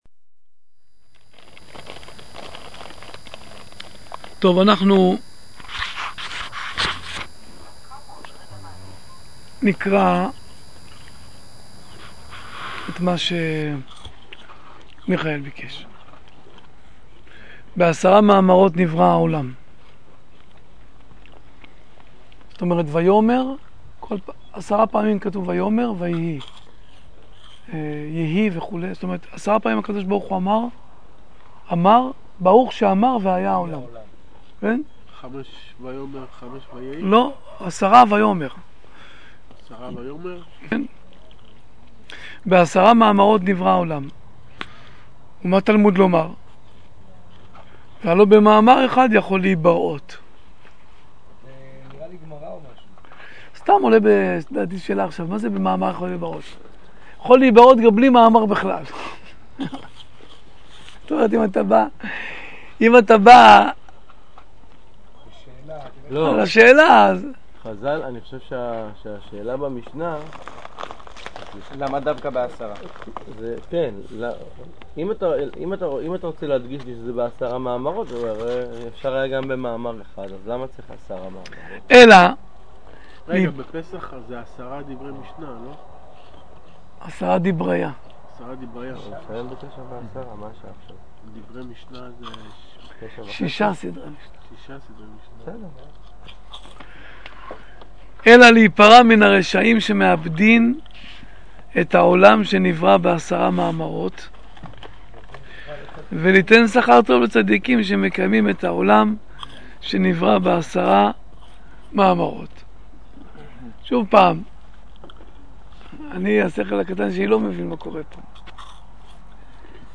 שיעורים לאורה של התורה של רבי נחמן מברסלו ומיועדים לבעלי תשובה. מתקיים בכל יום שלישי בערב בבית חגלה על פני יריחו